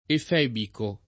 efebico [ ef $ biko ] agg.; pl. m. ‑ci